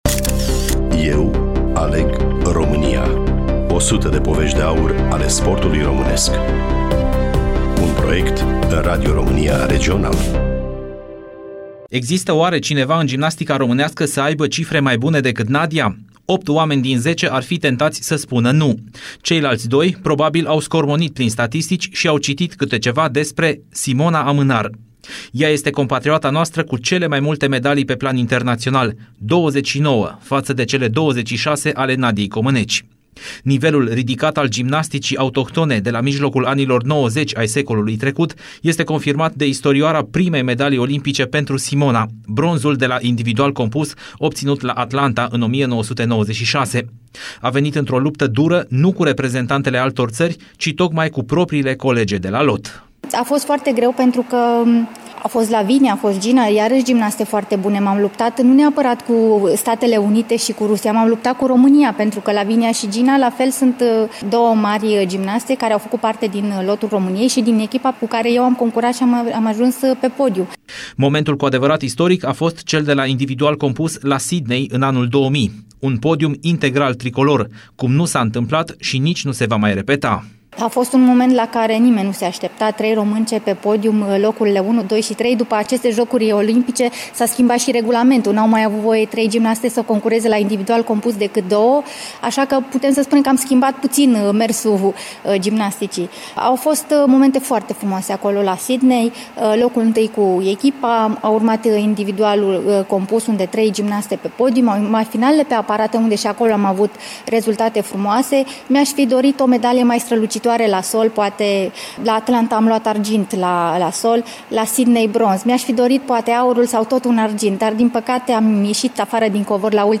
Studioul Radio Romania Timişoara